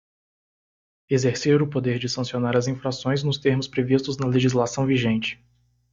Pronounced as (IPA)
/le.ʒiz.laˈsɐ̃w̃/